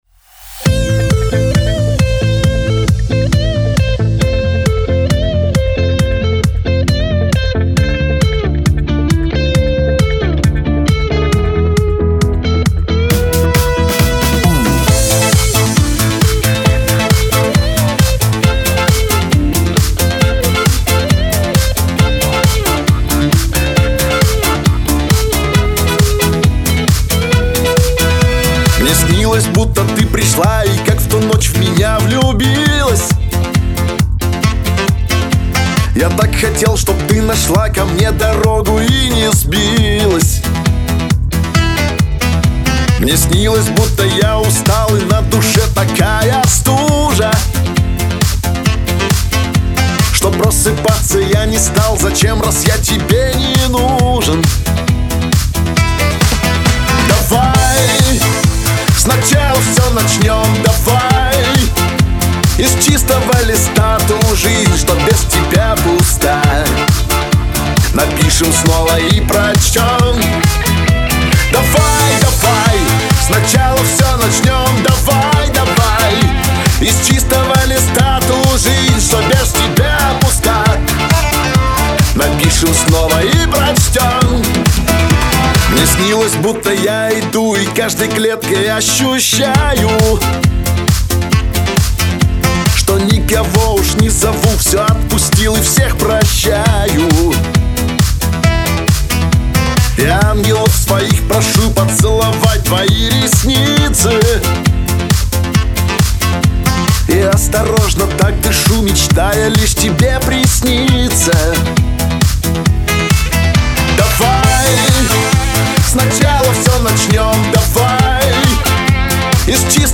Категория: Шансон 2016